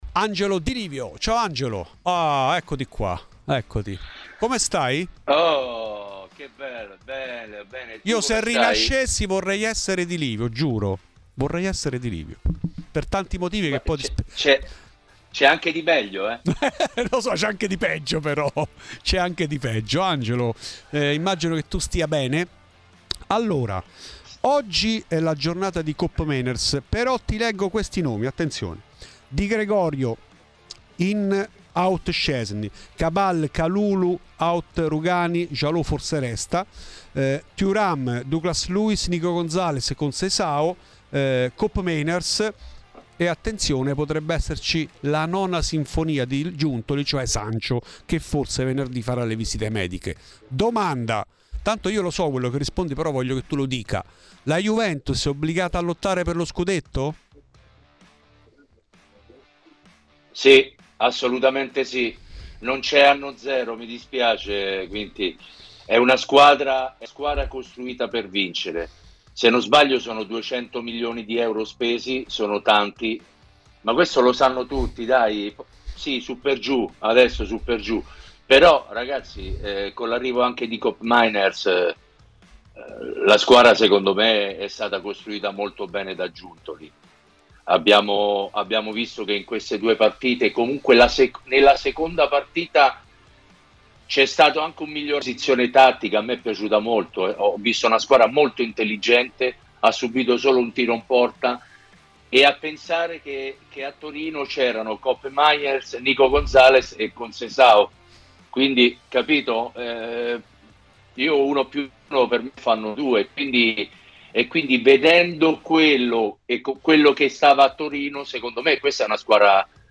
Angelo Di Livio è intervenuto negli studi romani di Radio Bianconera per parlare della nuova Juve, che vola tra campo e calciomercato